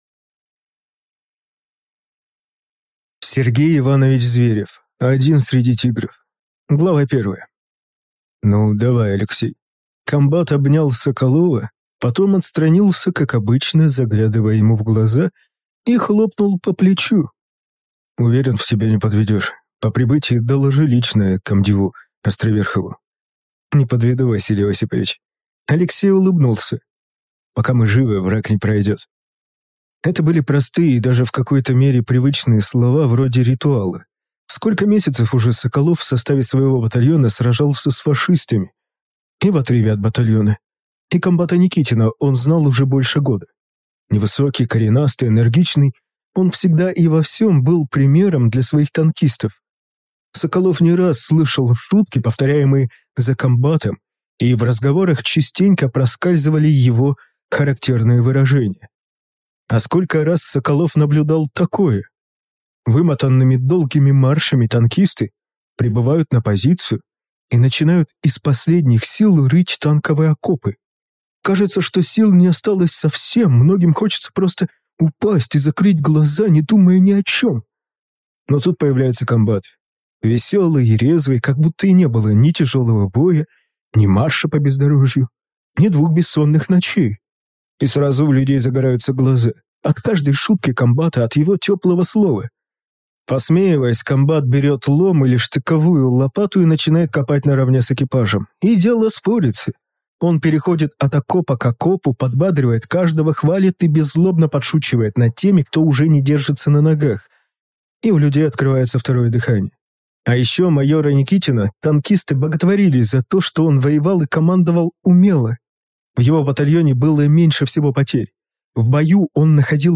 Аудиокнига Один среди «тигров» | Библиотека аудиокниг